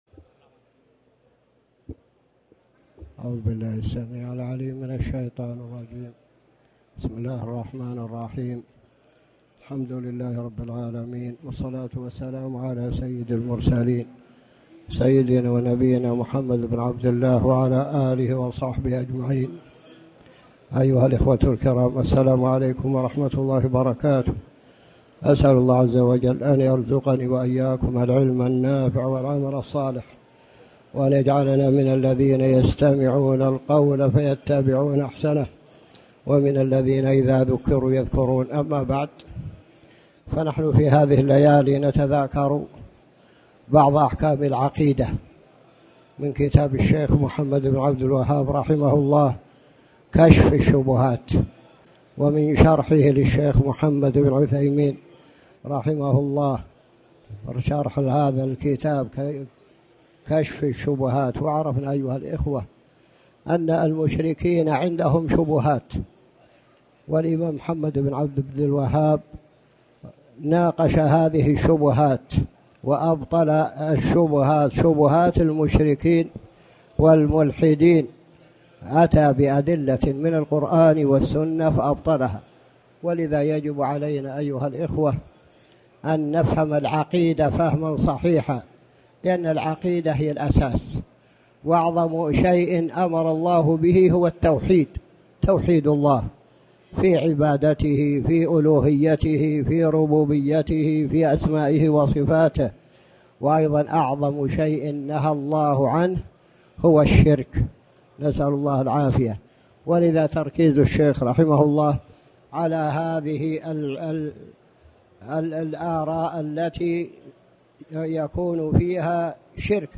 تاريخ النشر ٨ جمادى الآخرة ١٤٣٩ هـ المكان: المسجد الحرام الشيخ